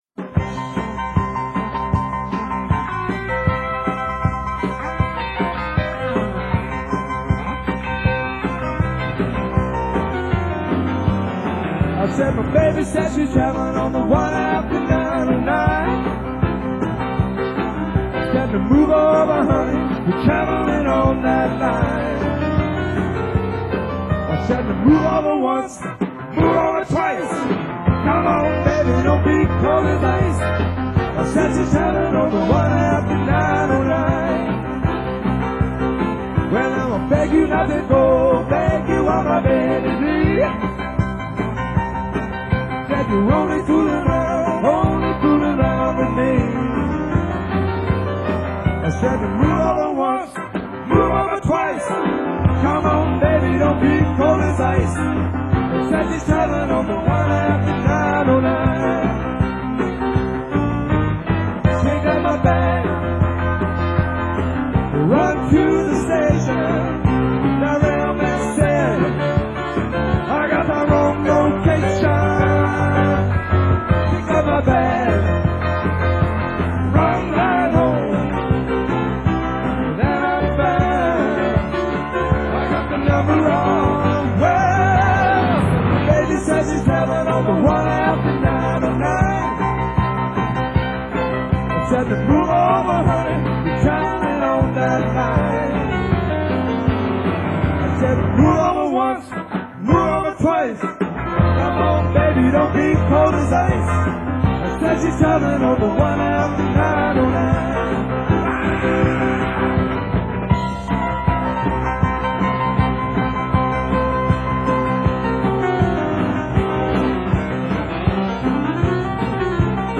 keyboards & vocals
drums
bass & vocals
guitar & vocals